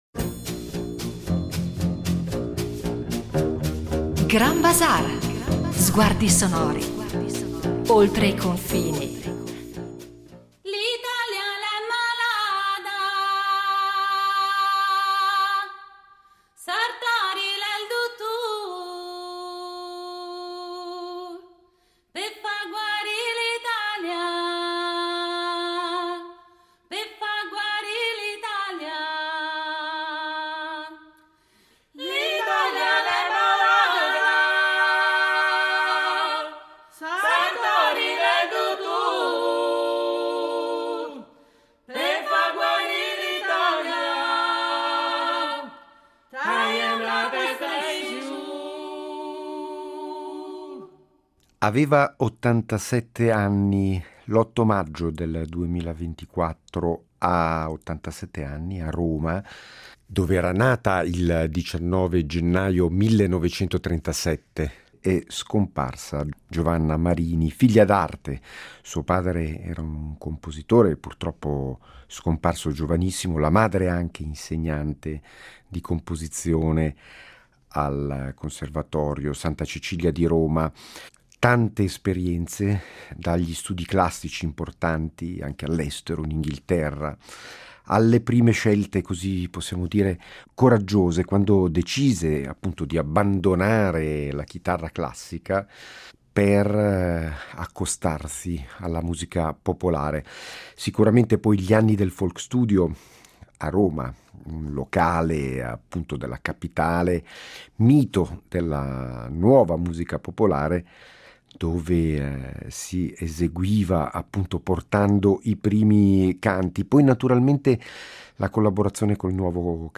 Un ritratto in due puntate di Grand Bazaar attraverso un’intervista ritrovata